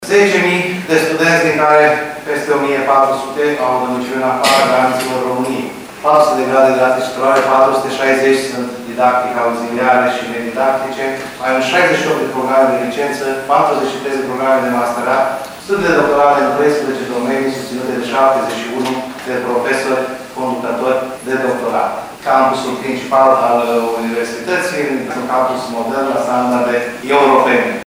Ea a participat la întâlnirea festivă desfășurată la USV și la care au fost invitați rectori din țară, Ucraina și Republica Moldova, precum și oficialități locale și județene.